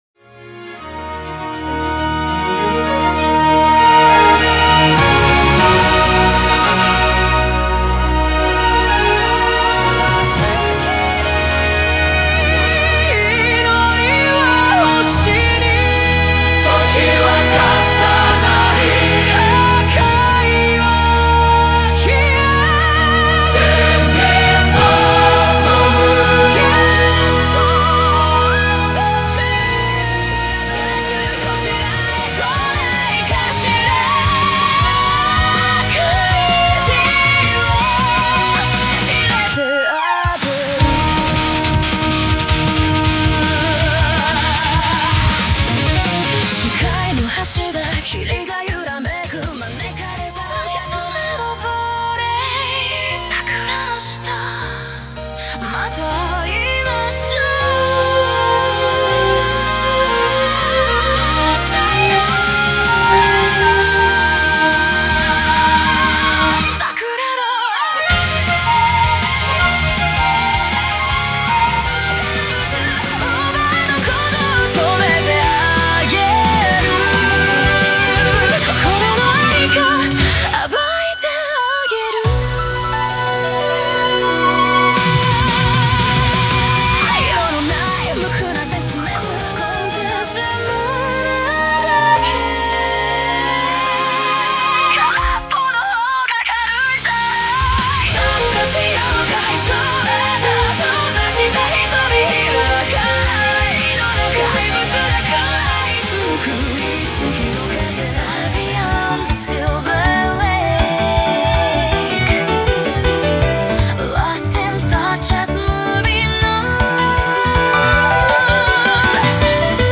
Music Demo / MP3
Violin Player
Strings Player
Piano Player
Human–Machine Hybrid Creation / 人と機械の融合制作